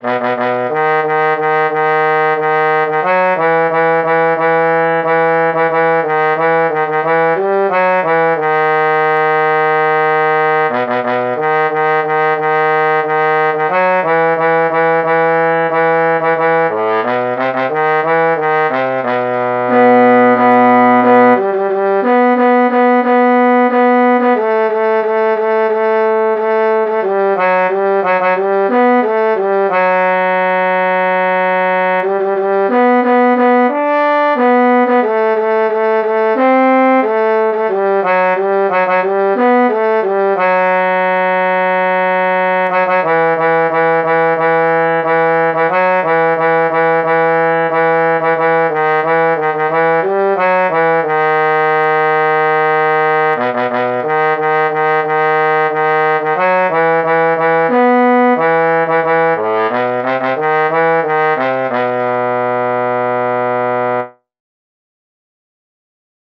Jewish Folk Song, popular among Chabad-Lubavitch
E minor ♩= 90 bpm